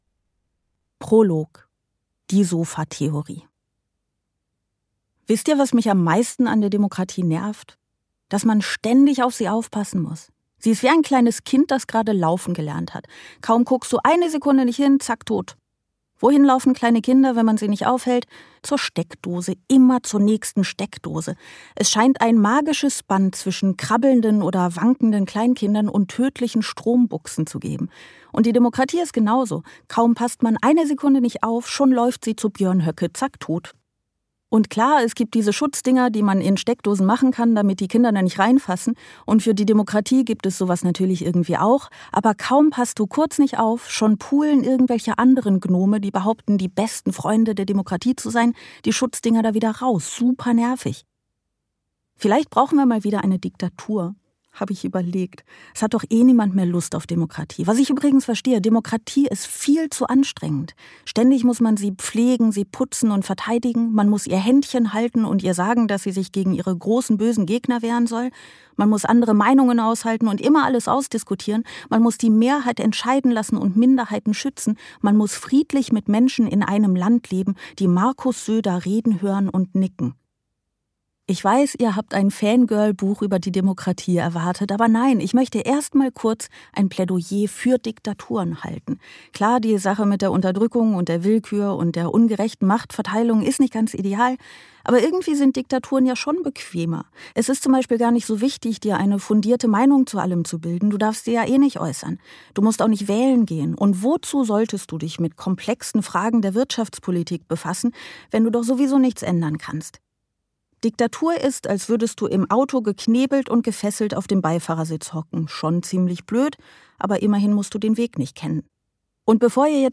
Ein ebenso lustiges wie entlarvendes Hörbuch über den Zustand der Demokratie und die Frage, wie man sie besser machen könnte.